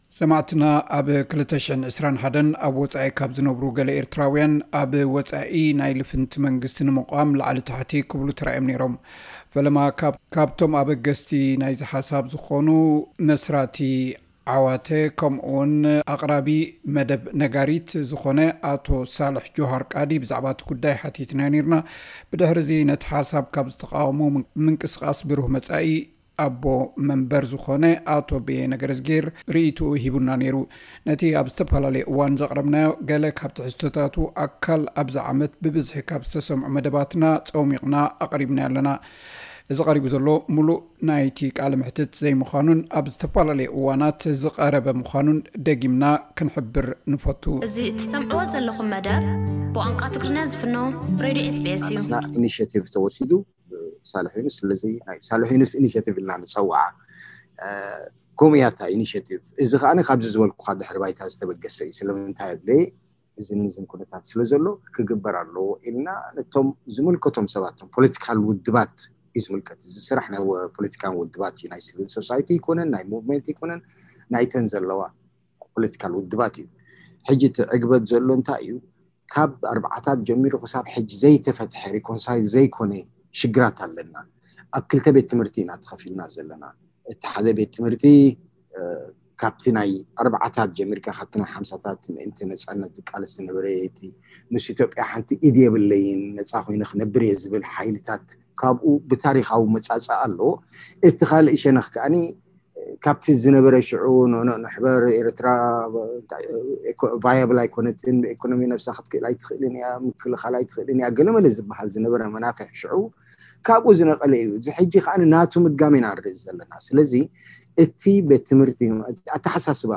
እዚ ቀሪቡ ዘሎ ምሉእ እቲ ቃለ መሕትት ዘይምዃኑን ኣብዝተፈላለየ እዋናት ዝቐረበ ምዃኑን ደጊምና ክንሕብር ንፈቱ።